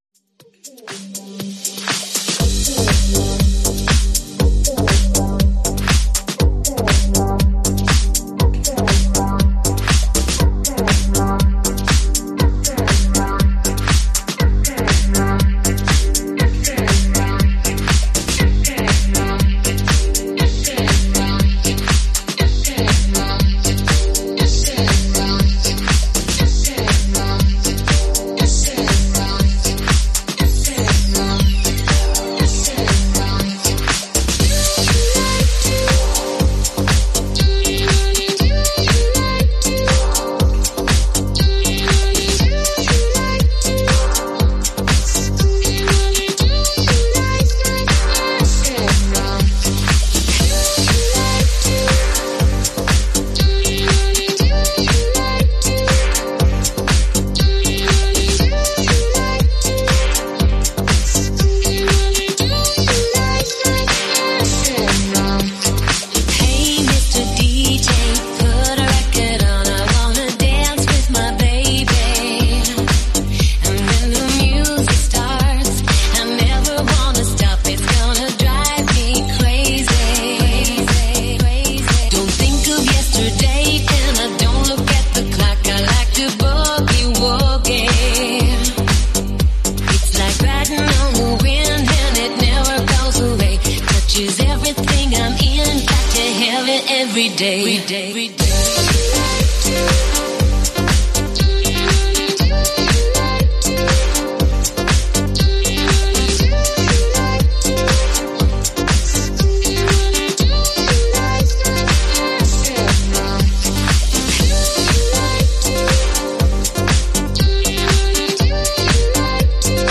format: 5" album / megamix